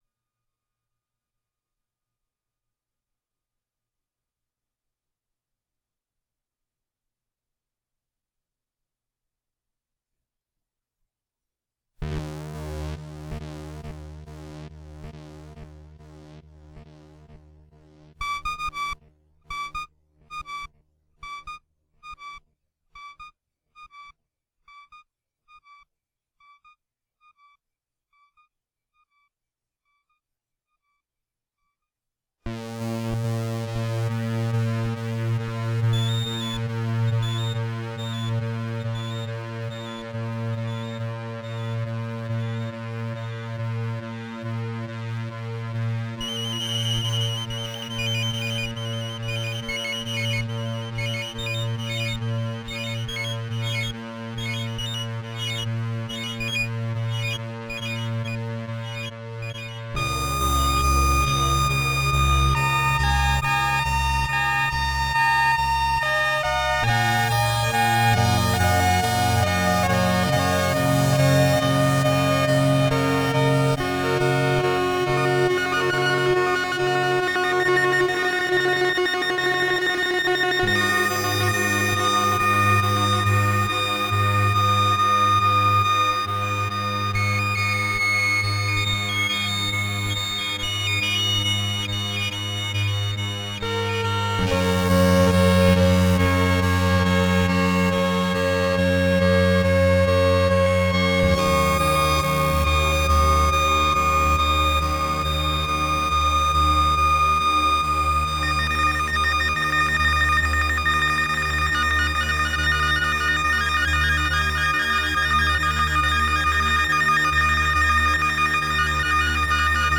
An ambient track made using Bristol's sidney C64 synth emulator. Like being in space, a return to a theme.